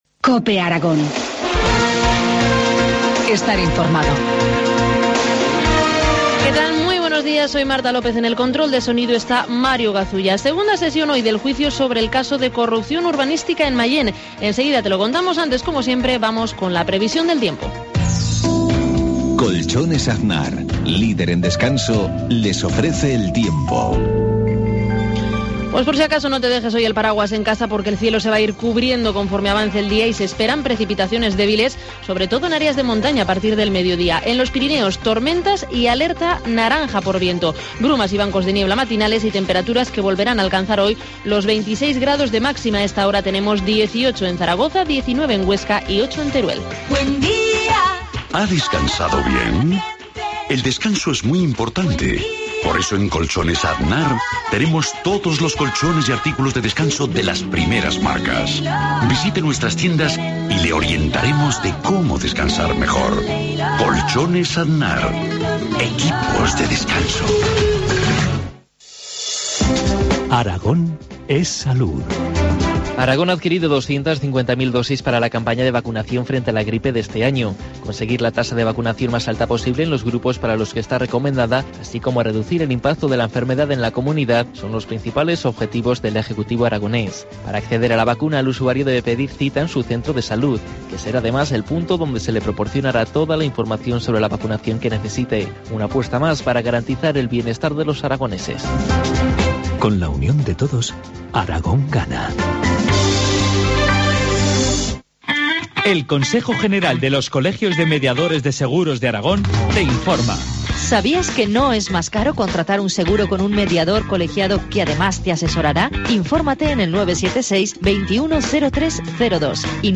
Informativo matinal, martes 22 de octubre, 7.53 horas